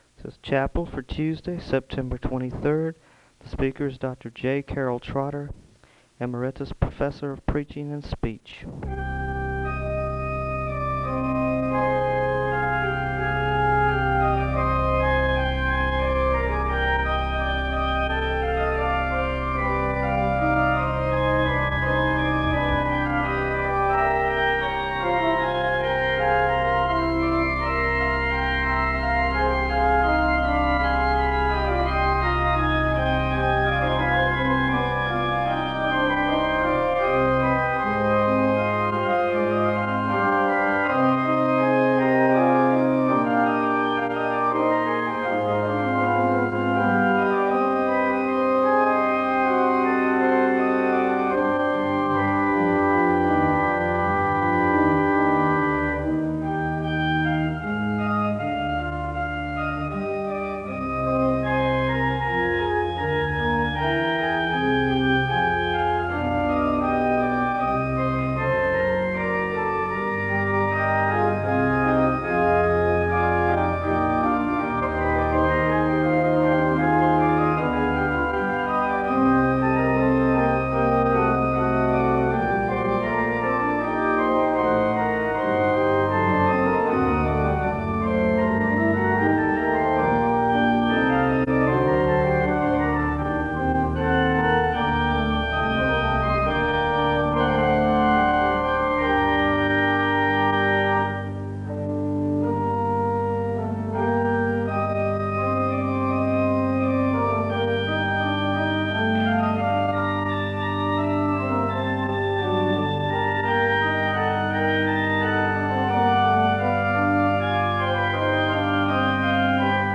The service begins with organ music (0:00-7:50).
Prayer concerns are shared with the congregation and there is a moment of prayer (9:16-11:54).
He speaks about the other side of death, using Lazarus and the rich man as examples of whether one goes to heaven or hell (16:16-34:37). There is a moment of prayer (34:38-35:11).